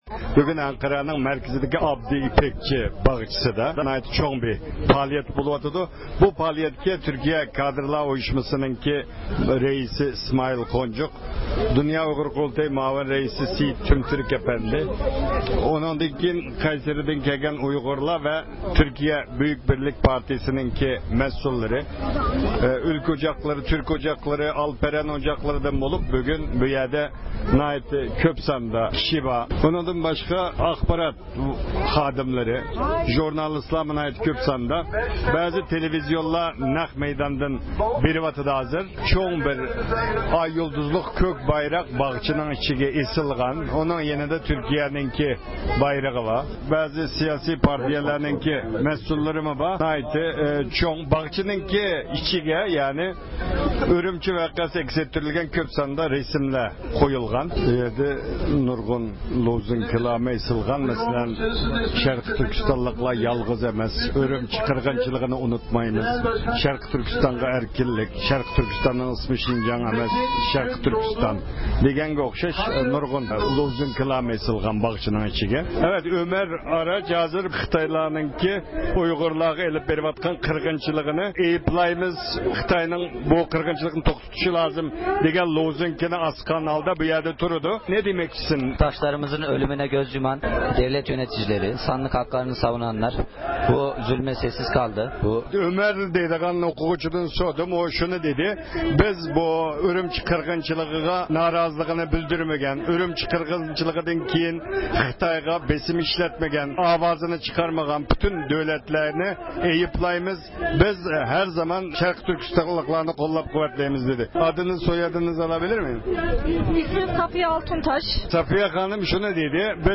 ئەنقەرە ئابدى ئىپەكچى باغچىسىدا ئۇيغۇرلارنى قوللاش يىغىلىشى ئۆتكۈزۈلدى – ئۇيغۇر مىللى ھەركىتى